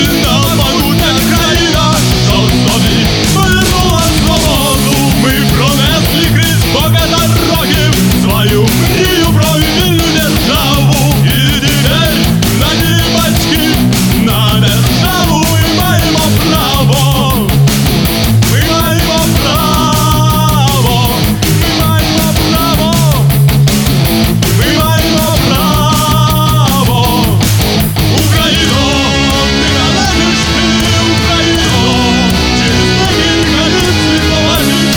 Жанр: Рок / Украинский рок / Украинские